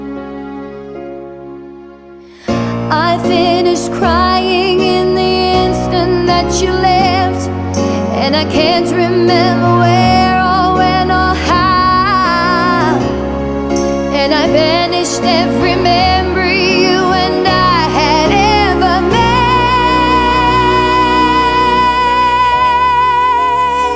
Single Version For Duet Rock 4:15 Buy £1.50